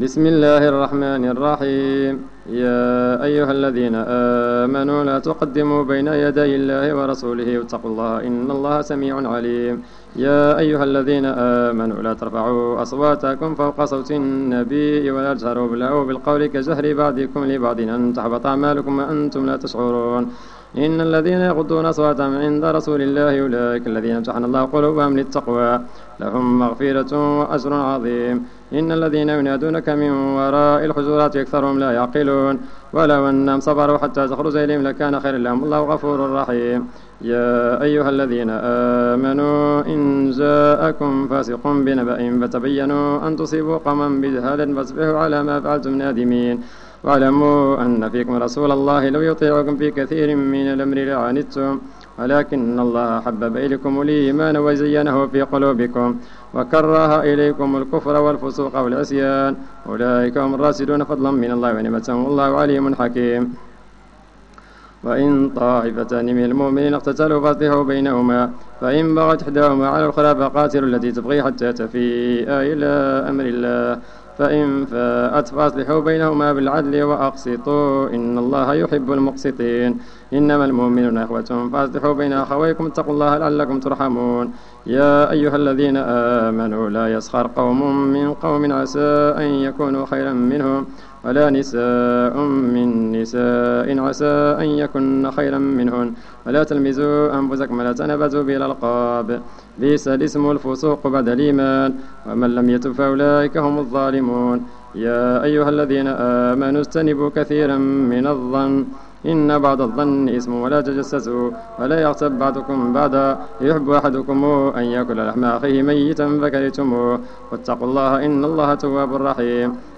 صلاة التراويح ليوم 25 رمضان 1431 بمسجد ابي بكر الصديق ف الزو
صلاة رقم 05 ليوم 25 رمضان 1431 الموافق سبتمبر 2010